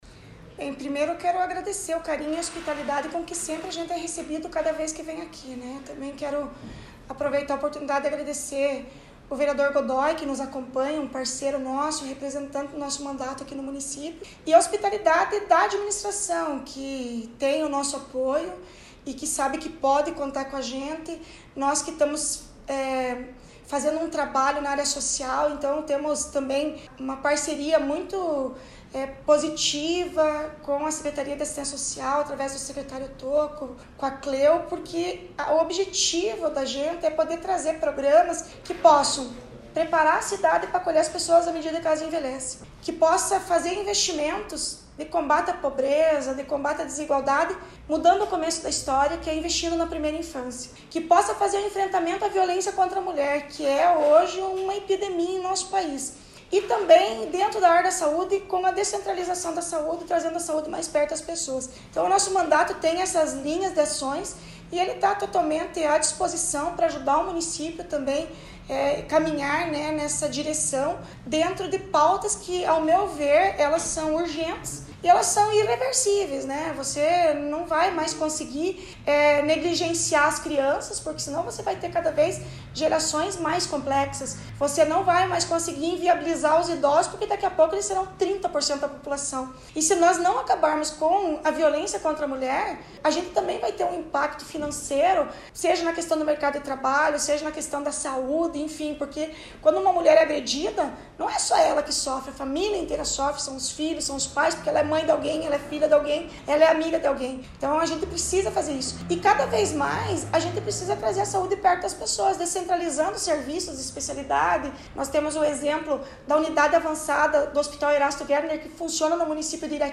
Acompanhe o áudio da deputada abaixo:
DEPUTADA-FEDERAL-LEANDRE-DAL-PONTE.mp3